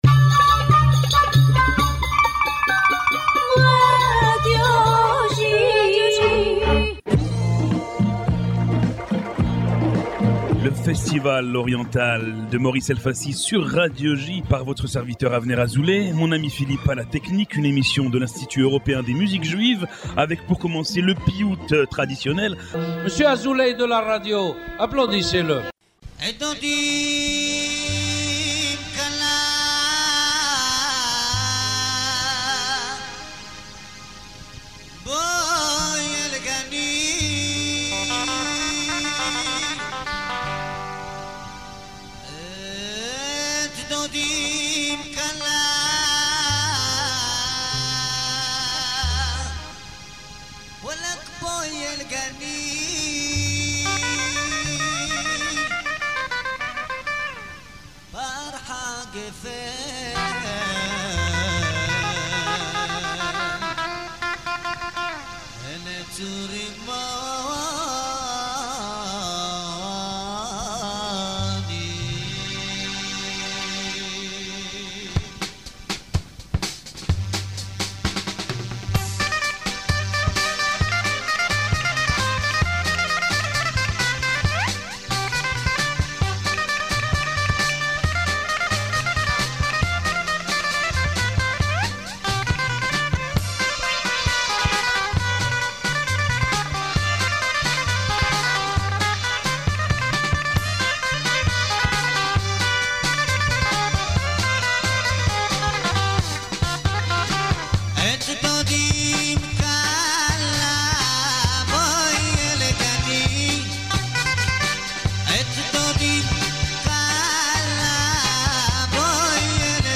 Broadcasted every Monday on Radio J (94.8 FM), « The Oriental festival » is a radio program from the European Institute of Jewish Music dedicated to Oriental Music.